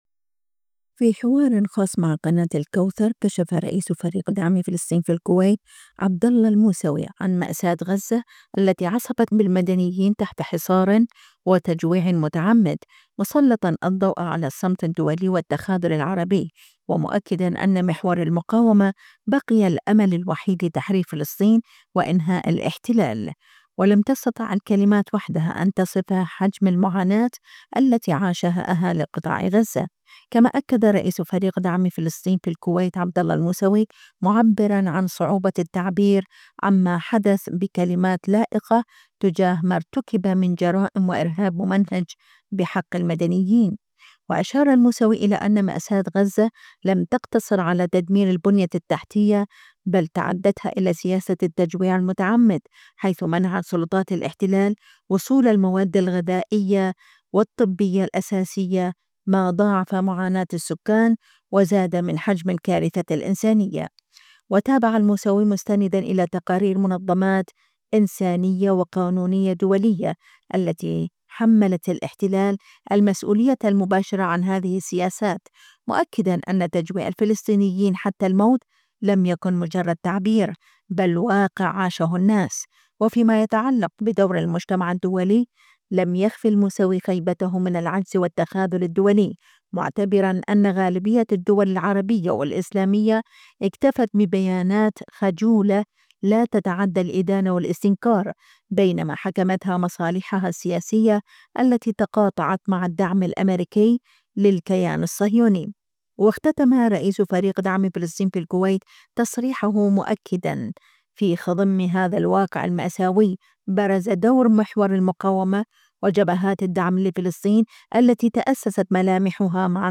خاص الكوثر - مقابلات